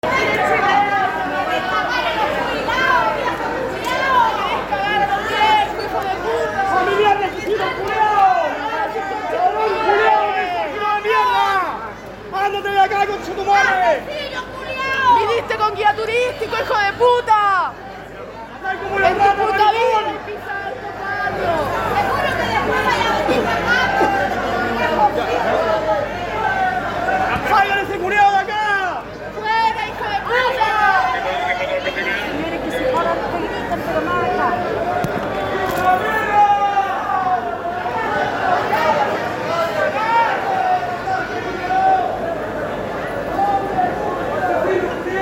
La visita de José Antonio Kast al tradicional Mercado Franklin terminó convertida en un tenso episodio luego de que el candidato presidencial del Partido Republicano fuera recibido con insultos y gritos por parte de comerciantes y vecinos del sector.
Un grupo de locatarios y detractores expresó su rechazo a Kast con fuertes gritos e insultos.
Las expresiones se multiplicaron a medida que avanzaba la comitiva, generando un ambiente hostil que impidió cualquier diálogo.
kast_es_expulsado_del_barrio_franklin.mp3